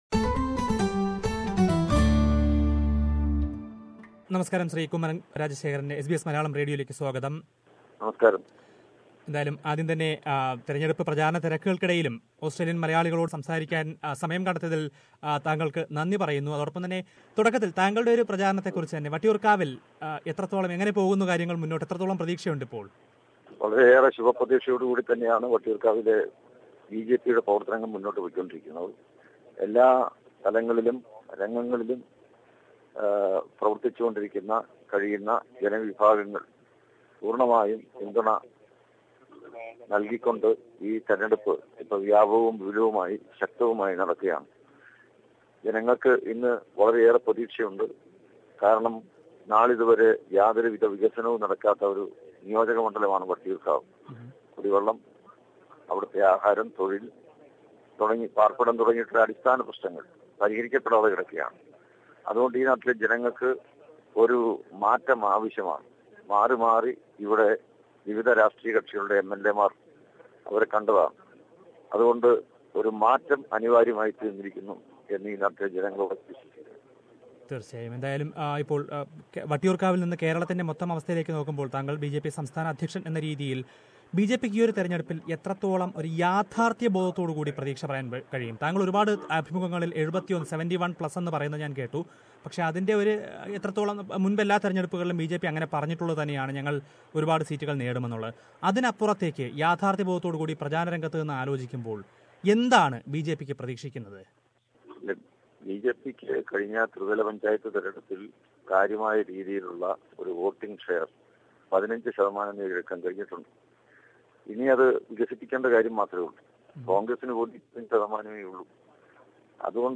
Interview with Kummanam Rajashekharan